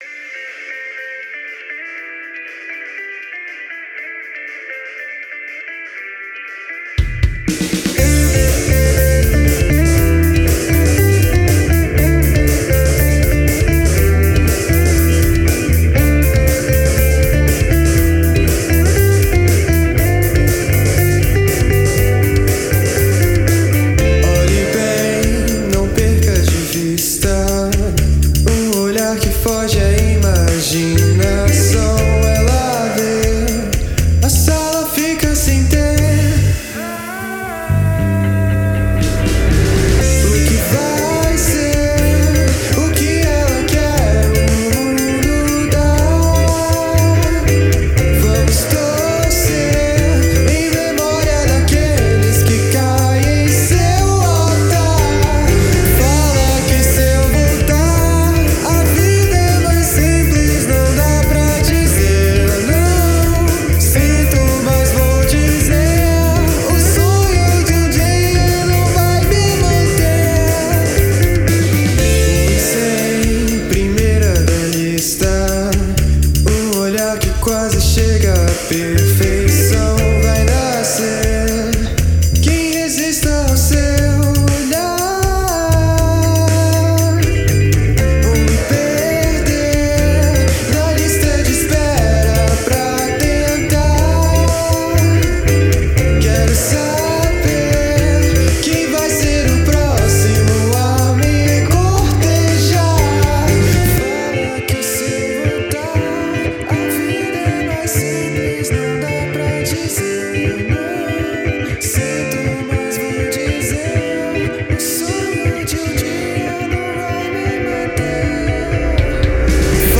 EstiloIndie